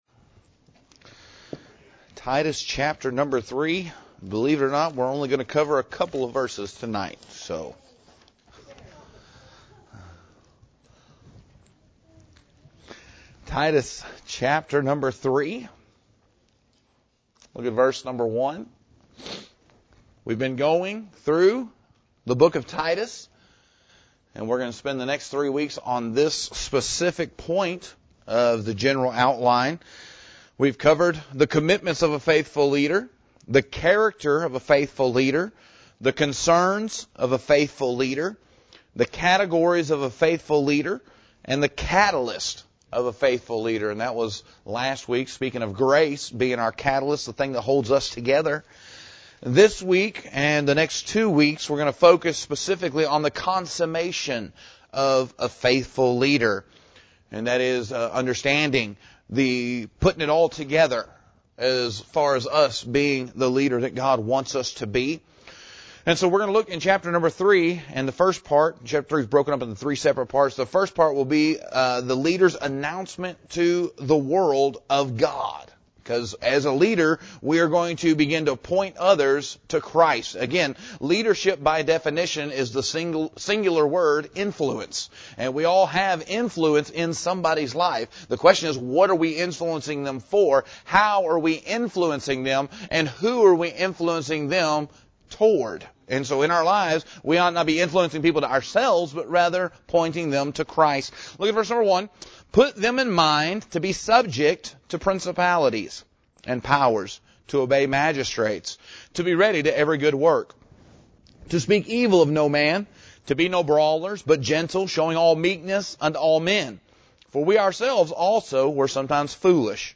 Leading Like Jesus – Part 5 – Cornerstone Baptist Church | McAlester, OK